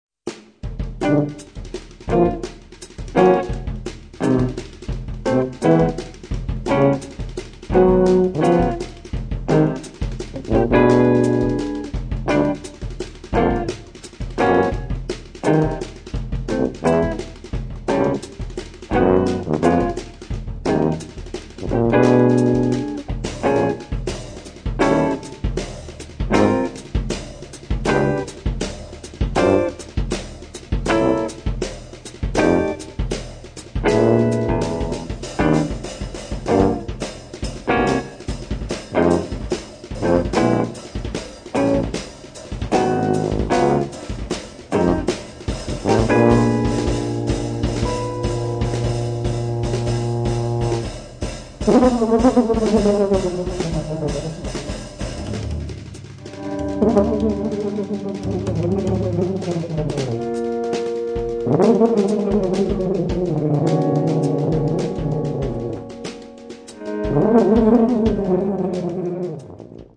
guitar, electronics
tuba
drums, percussion